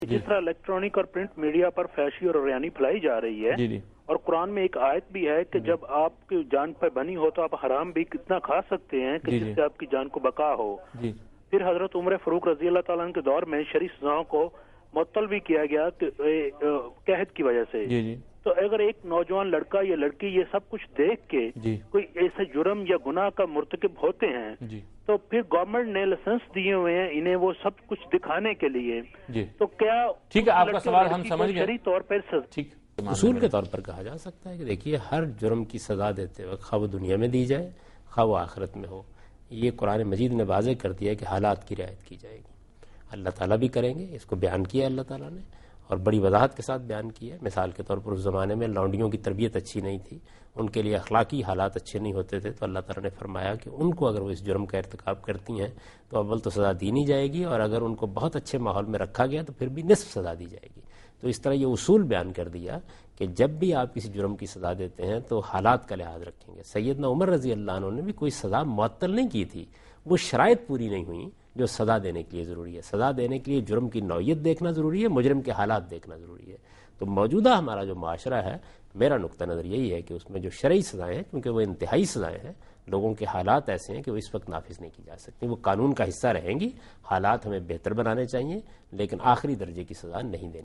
Answer to a Question by Javed Ahmad Ghamidi during a talk show "Deen o Danish" on Duny News TV
دنیا نیوز کے پروگرام دین و دانش میں جاوید احمد غامدی ”سزاؤں کا نفاذ اور حالات کی رعایت“ سے متعلق ایک سوال کا جواب دے رہے ہیں